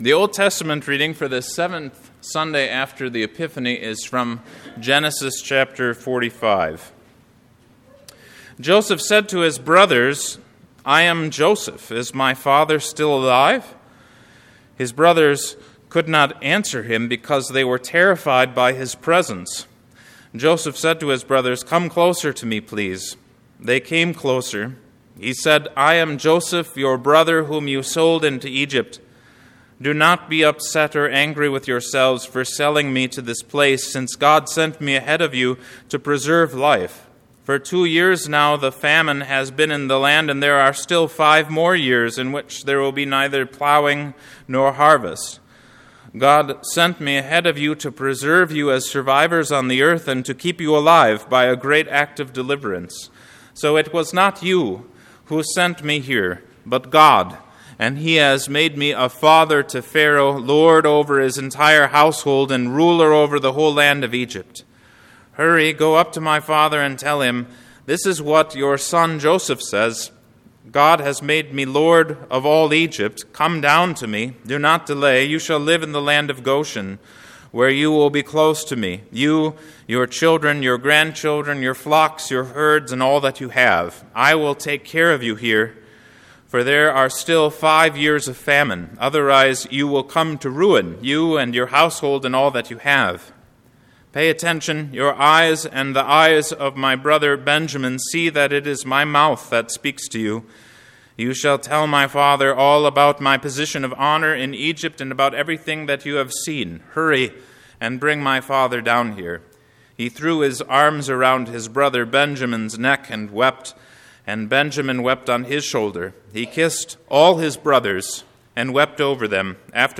220220 Sermon on Genesis 45:3-15 (Epiphany 7C) February 20, 2022